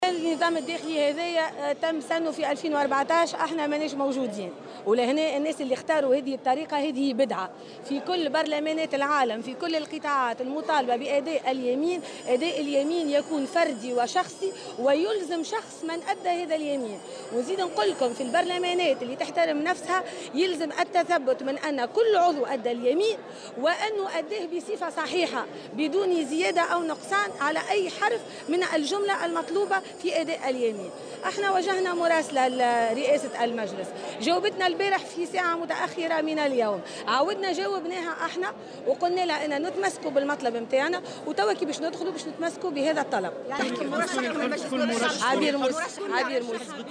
قالت رئيسة الحزب الدستوري الحر، عبير موسي في تصريح اليوم لمراسل "الجوهرة أف أم" على هامش الجلسة الافتتاحية لمجلس نواب الشعب إن حزبها قرّر ترشيحها لرئاسة البرلمان.